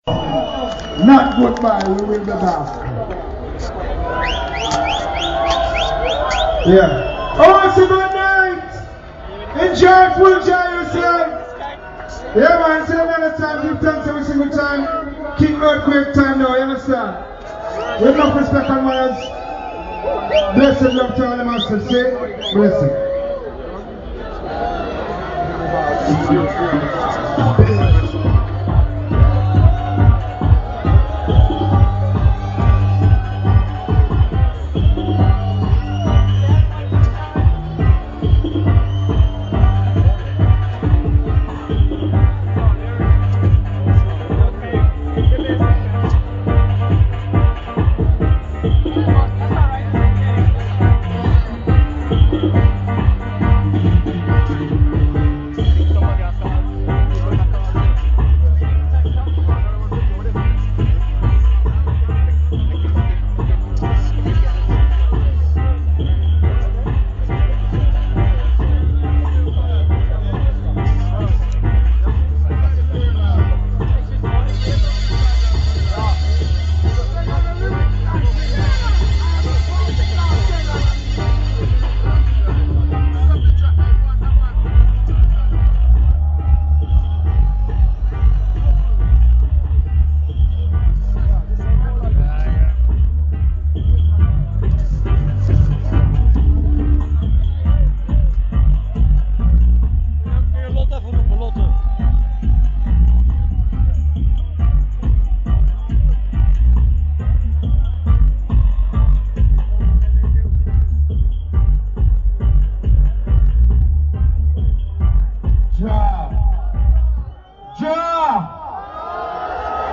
reggae geel 2010 21inch corner